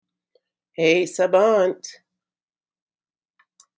wake-word